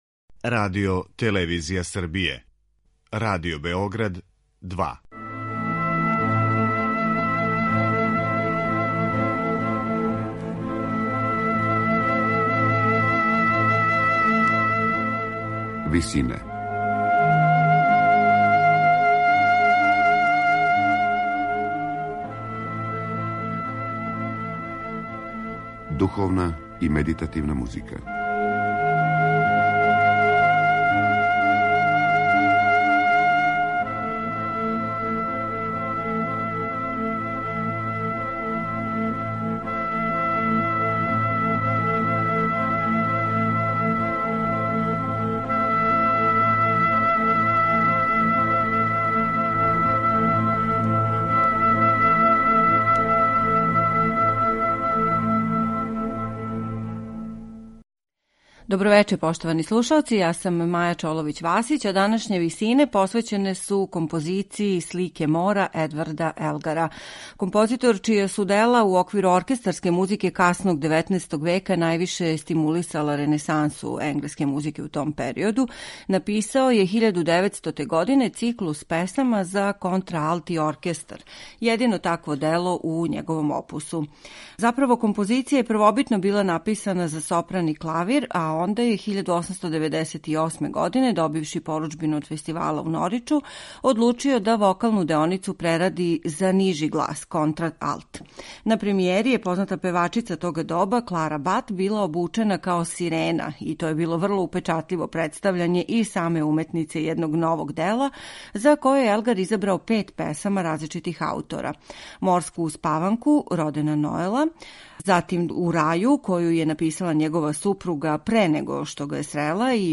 Емисија је посвећена циклусу за контраалт и оркестар „Слике мора” енглеског композитора Едварда Елгара.